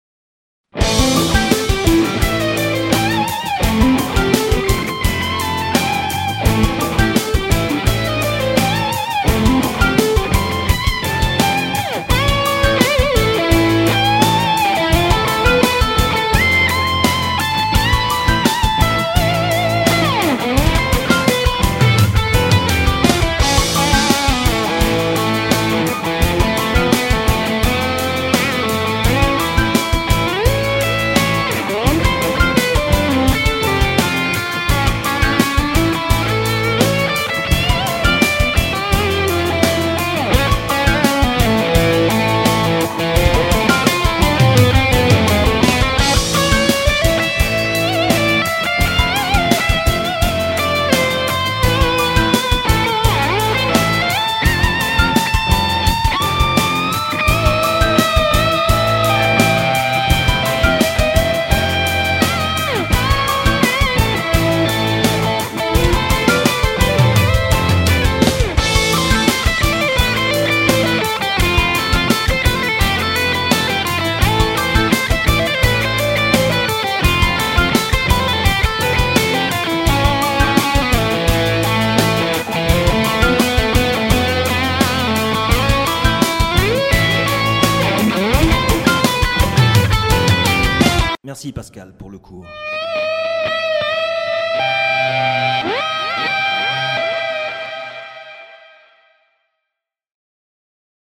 on peut avoir ce genre de sonorité aussi avec le super 30 VHT la partie solo